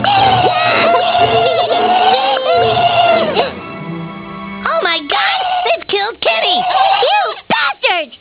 KILLED KENNY Stan saying oh my god they killed kenny.......